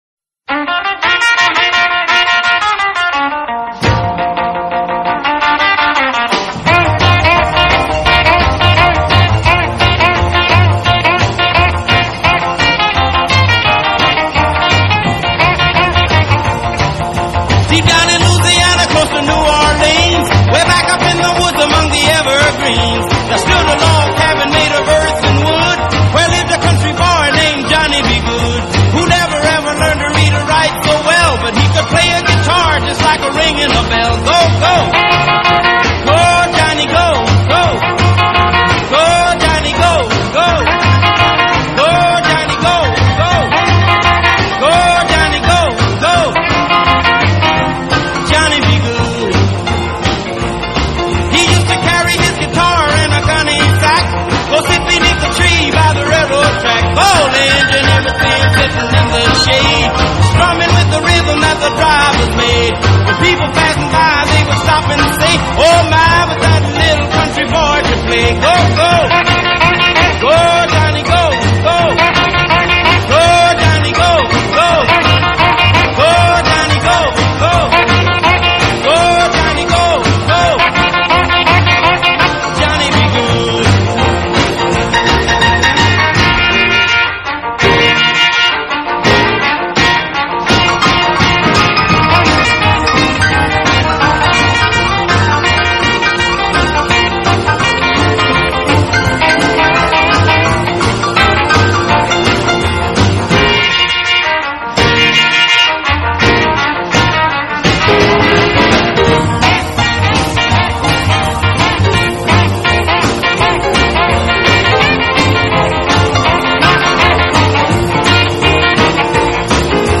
Rock and Roll